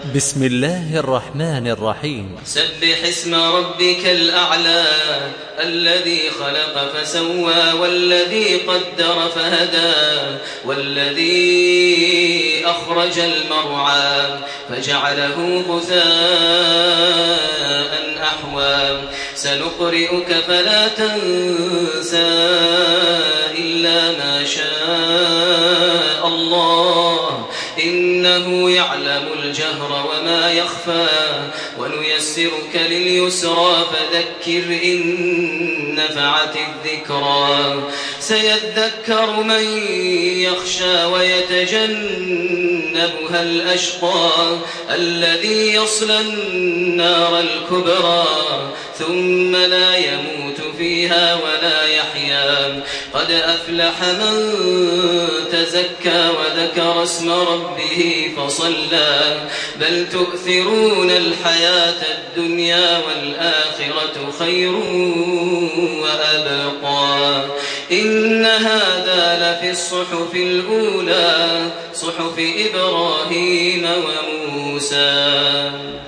Surah আল-আ‘লা MP3 by Makkah Taraweeh 1428 in Hafs An Asim narration.